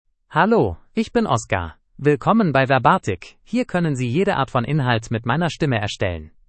OscarMale German AI voice
Oscar is a male AI voice for German (Germany).
Voice sample
Listen to Oscar's male German voice.
Male